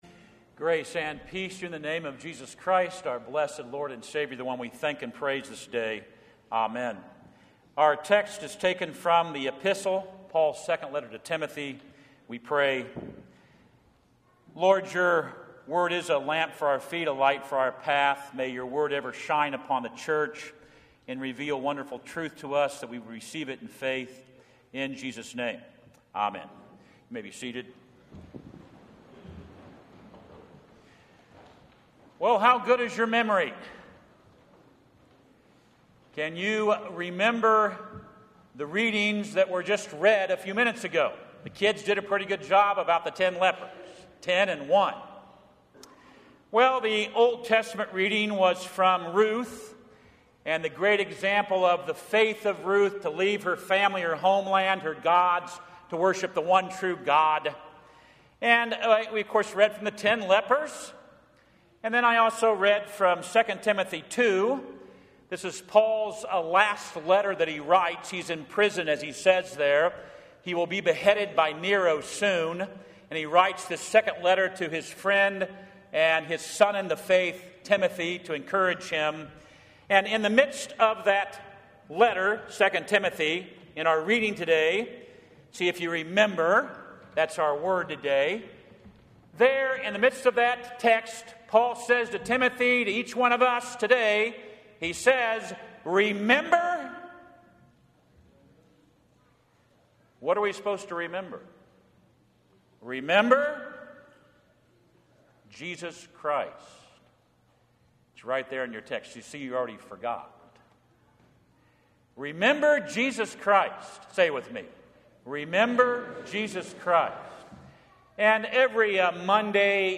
2 Timothy 2:1-13 Audio Sermon